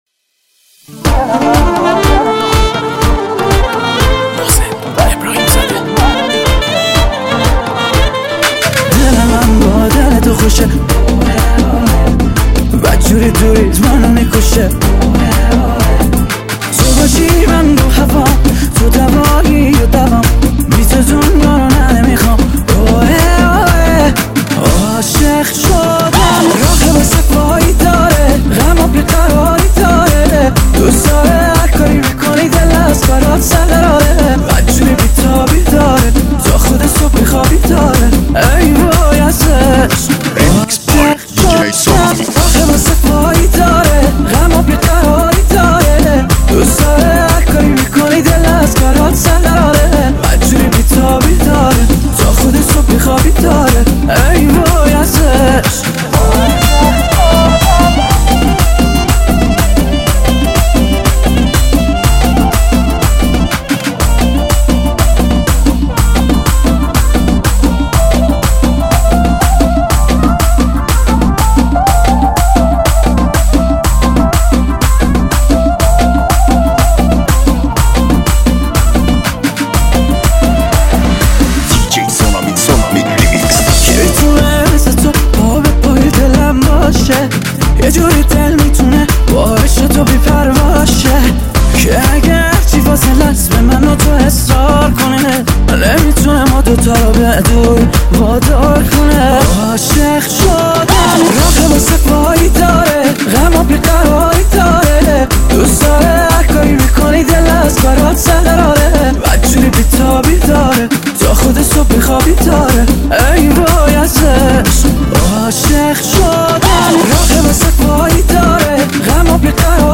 دانلود ریمیکس شاد جدید
ریمیکس شاد ارکستی